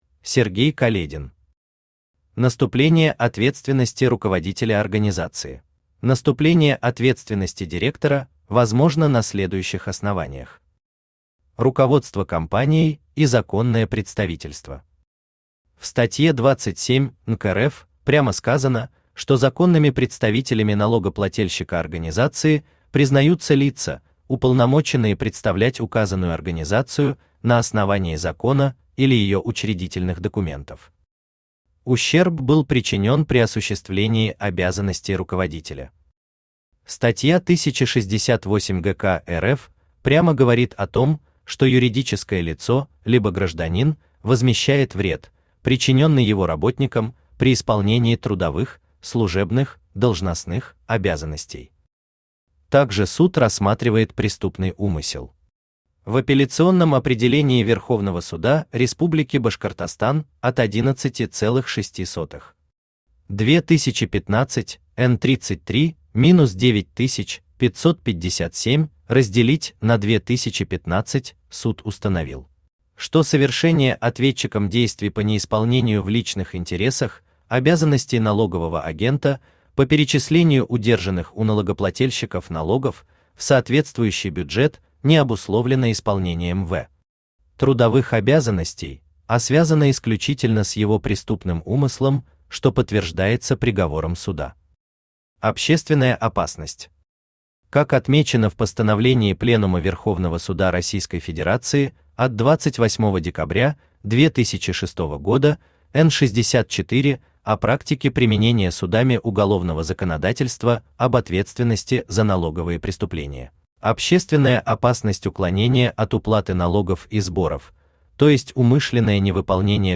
Аудиокнига Наступление ответственности руководителя организации | Библиотека аудиокниг
Aудиокнига Наступление ответственности руководителя организации Автор Сергей Каледин Читает аудиокнигу Авточтец ЛитРес.